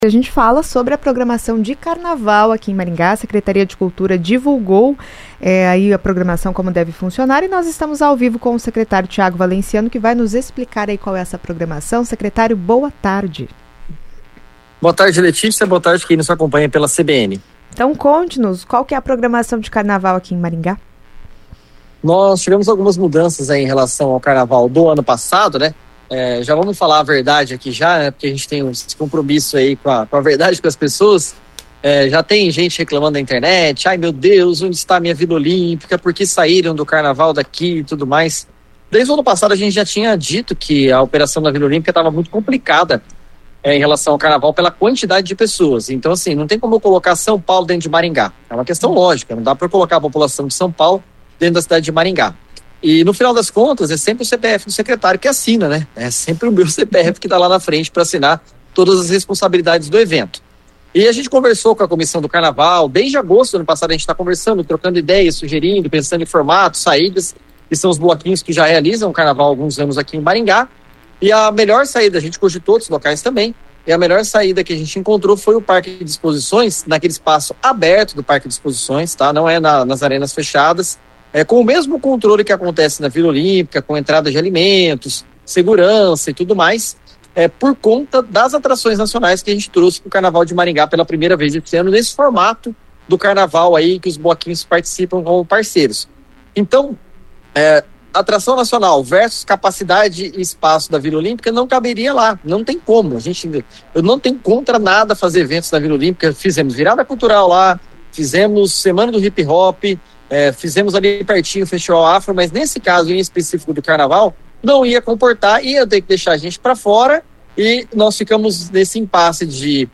O edital para inscrição dos bloquinhos carnavalescos deve ser publicado em breve, assim como outras atrações, explica o secretário. Ouça a entrevista.